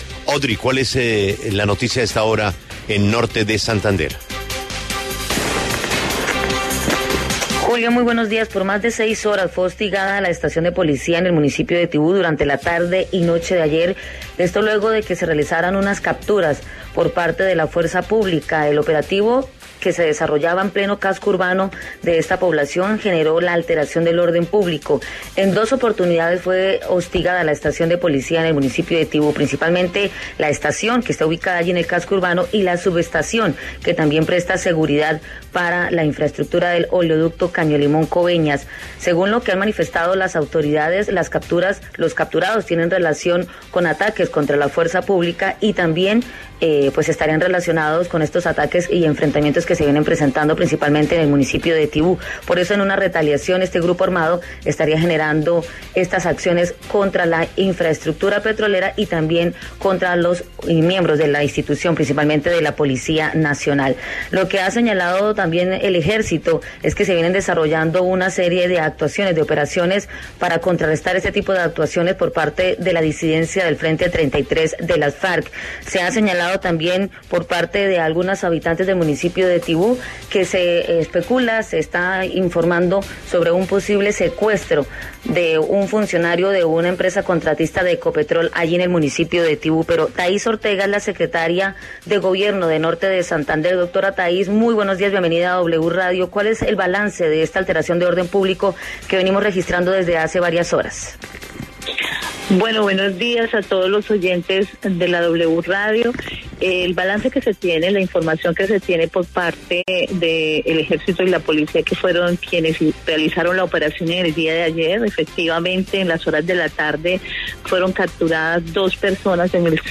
En diálogo con La W, Taiz Ortega, secretaria de Gobierno de Norte de Santander, hizo un balance sobre la situación de orden público en esta zona del departamento.